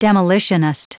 Prononciation du mot demolitionist en anglais (fichier audio)
Prononciation du mot : demolitionist